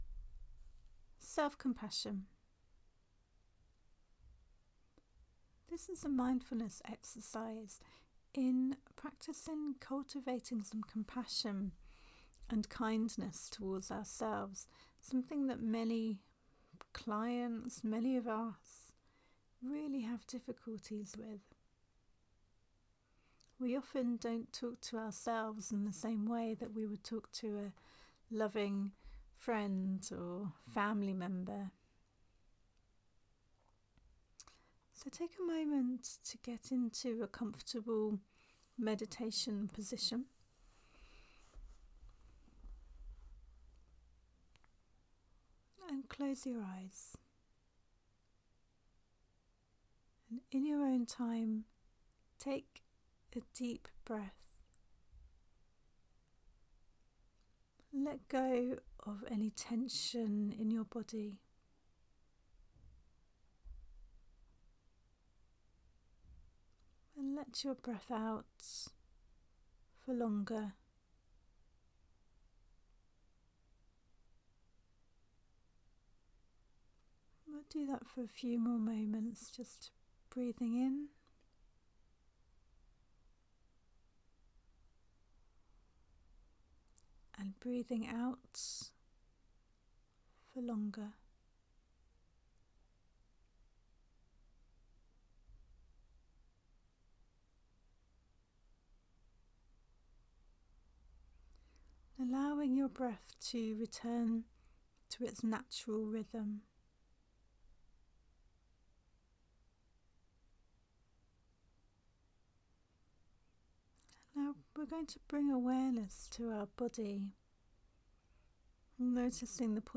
For an exercise in developing self compassion, download